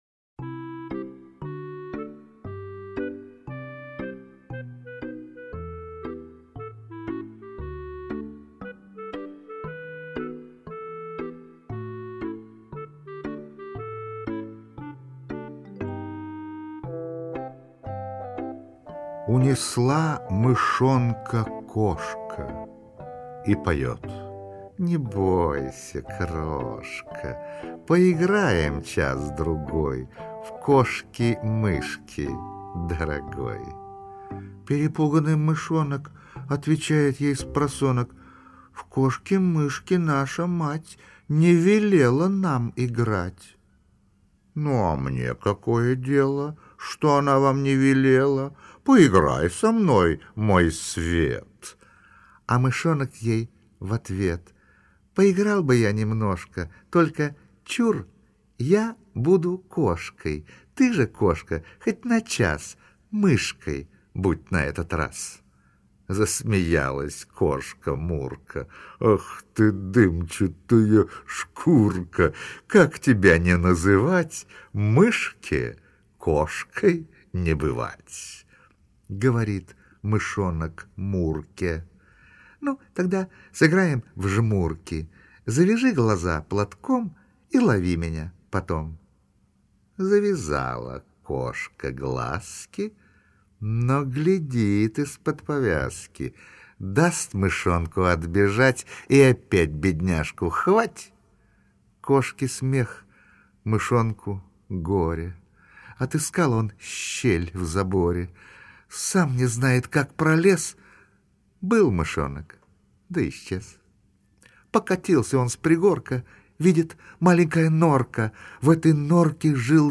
Ya.-Marshak-Skazka-ob-umnom-myshonke-chitaet-O.Basilashvili-stih-club-ru.mp3